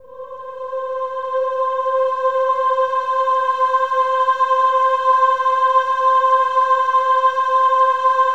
OH-AH  C5 -R.wav